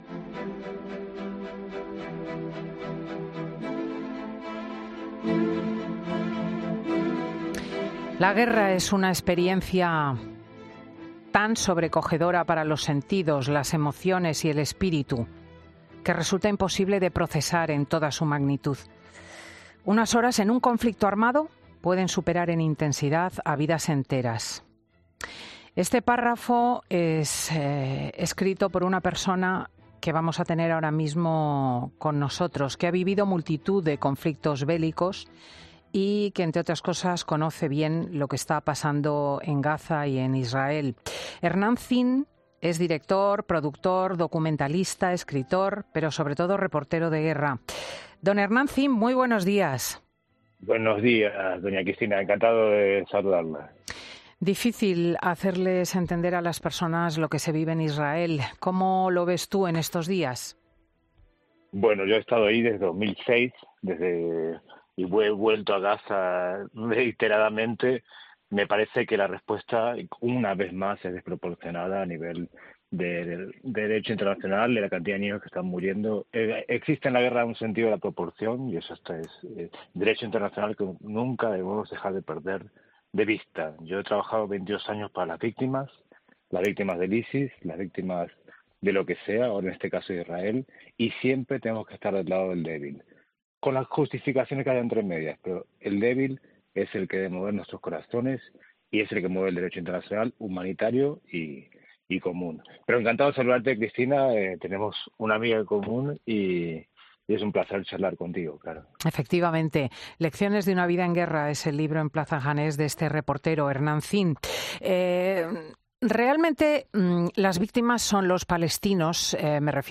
El director, productor, escritor y reportero de guerra italo-argentino participa en Fin de Semana